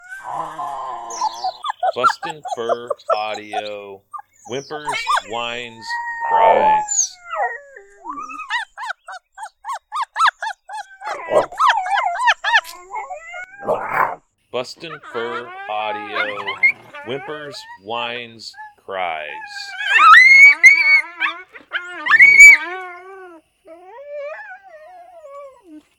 BFA Whimpers Whines Cries
Coyotes typically fight and bicker amongst each other, whimpers, whines and cries are common social sounds created, when paired up.
BFA Whimpers Whines Cries Sample.mp3